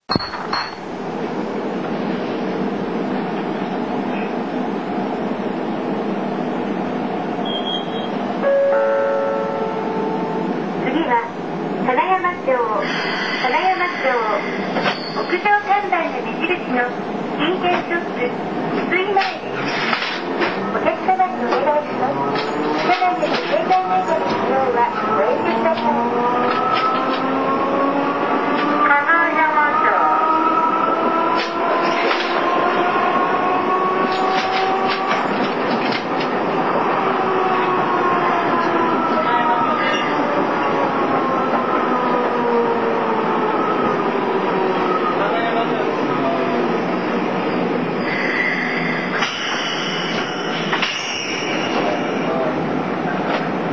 ■車内で聴ける音■